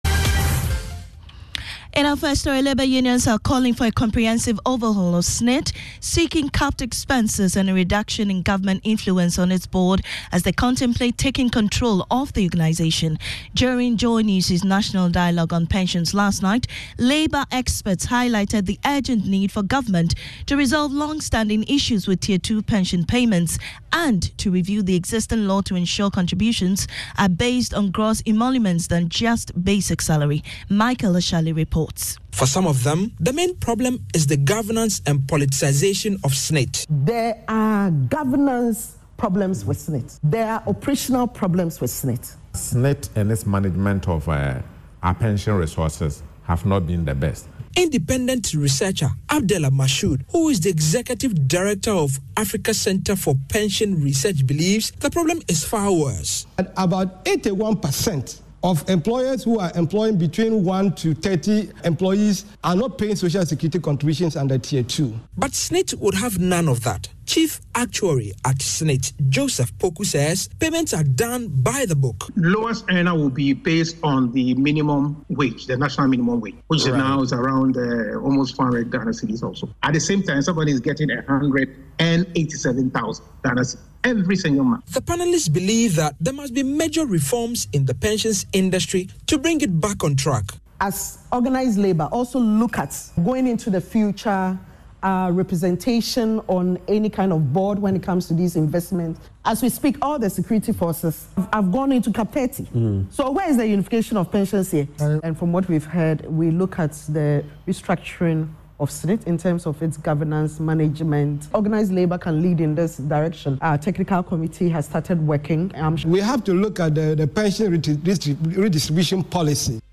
Contributing to a discourse on pension reforms on JoyNews' National Dialogue on July 25, 2024, she highlighted concerns about SSNIT's investments not benefiting workers adequately.